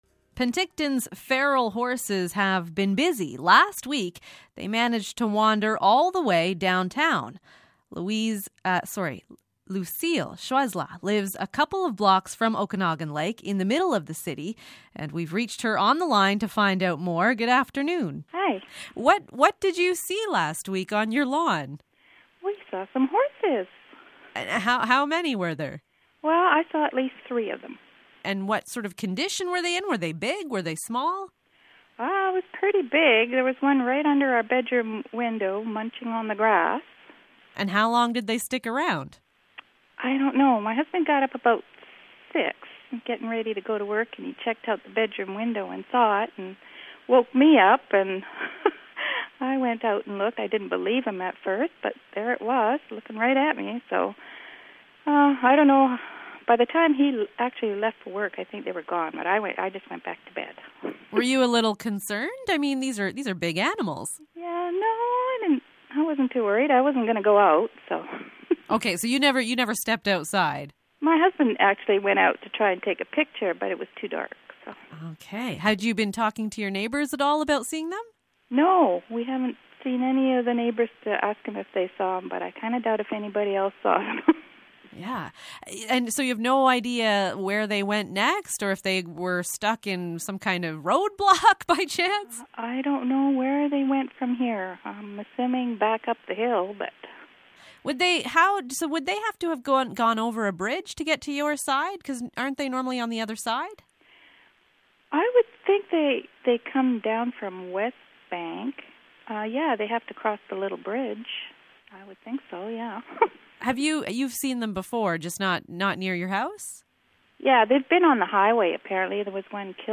Feral horses in Penticton are moving right into the downtown area of Penticton. You can hear a women who saw the horses last week, and then an update from the Regional District of Okanagan Similkameen. There are at least 600 feral horses in the area.